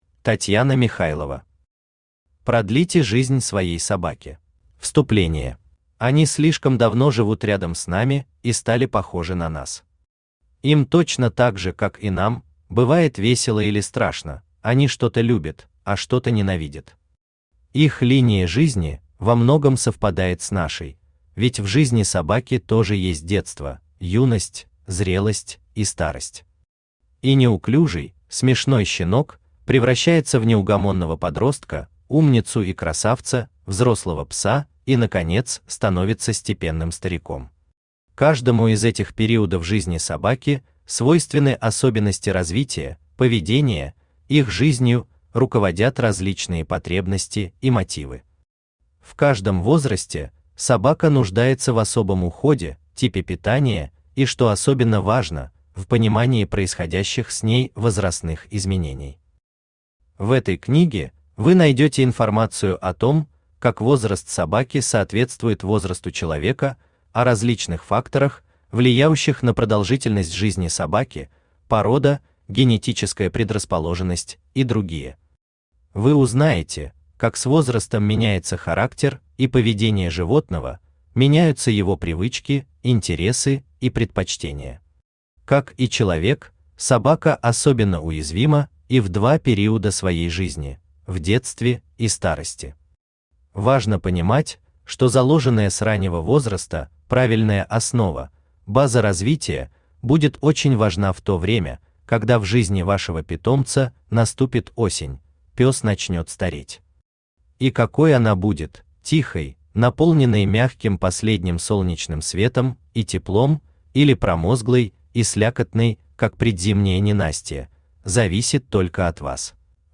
Аудиокнига Продлите жизнь своей собаке | Библиотека аудиокниг
Aудиокнига Продлите жизнь своей собаке Автор Татьяна Михайлова Читает аудиокнигу Авточтец ЛитРес.